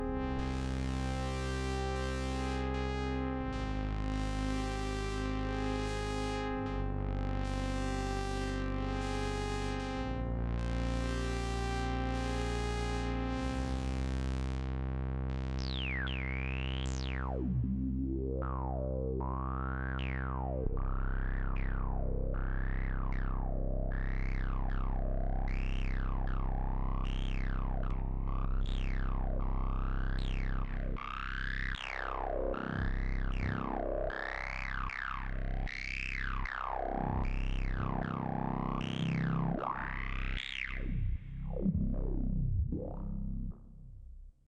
this is just a sound i recorded to my octatrack 3 minutes ago. it’s not a great representation of how vast and gritty it can be, but it does use fm, osc sync, audio rate modulation of a few different parameters, then i use the hp filter effect with some high res filter cutoff at the end there